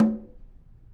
Snare2-HitNS_v1_rr2_Sum.wav